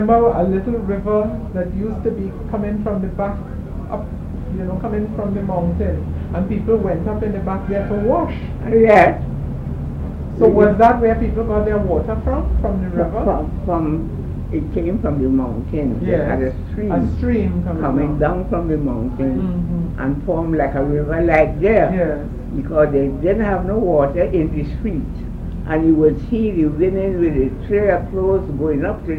1 audio cassette and 1 video cassette
Oral Tradition, Oral History, History Teaching, Social Conditions, Water Supply